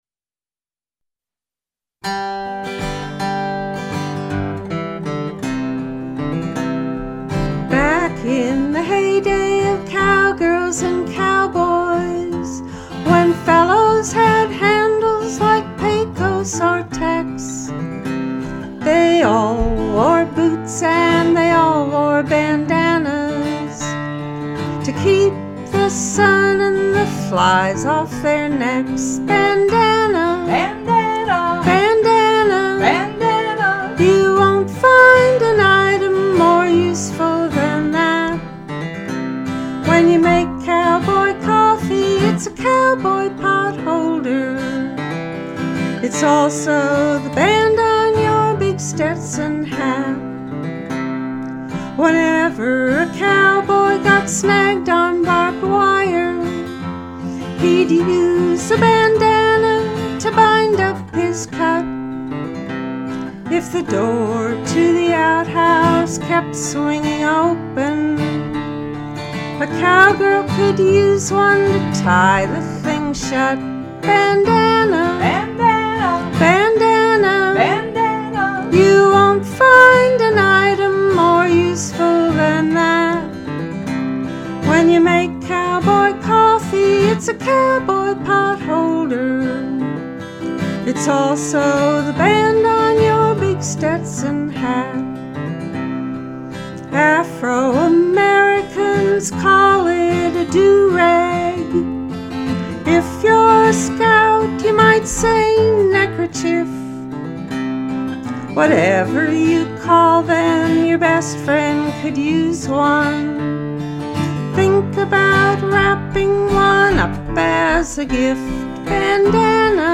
backup vocal and guitar